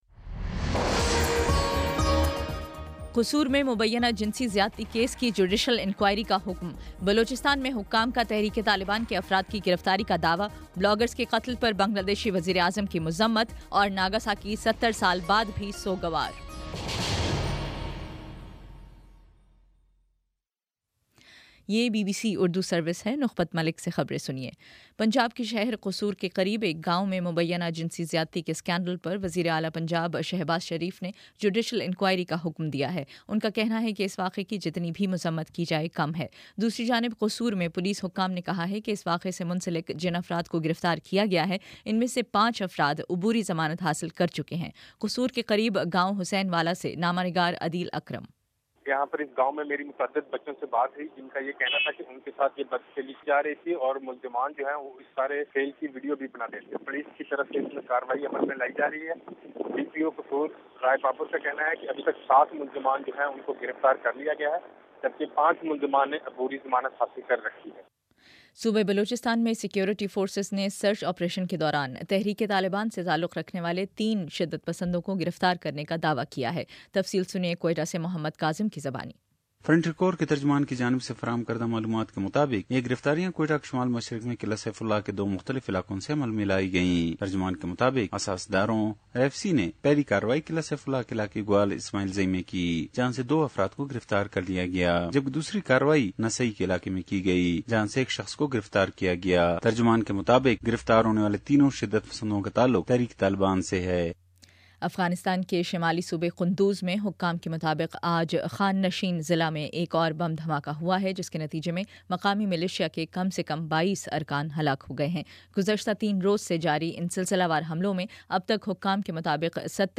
اگست 09: شام چھ بجے کا نیوز بُلیٹن